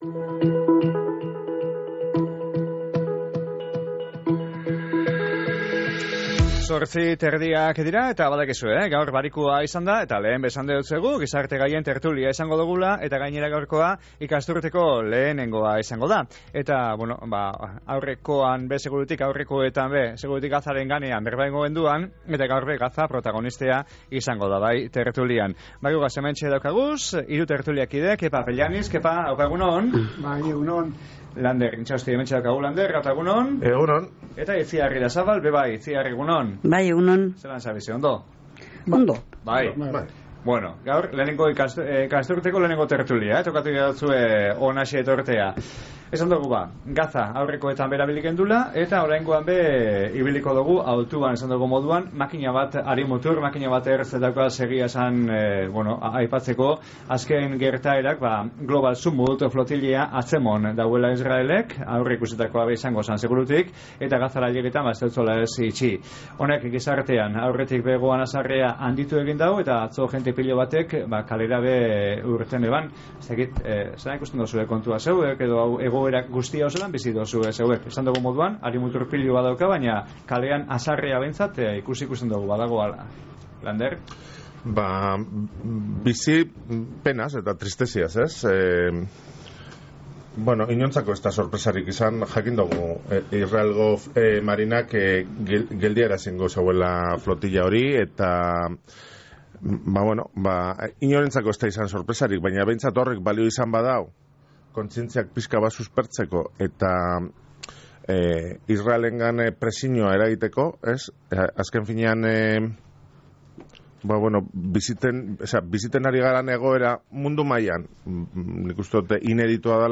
GIZARTE-GAIEN-TERTULILA.mp3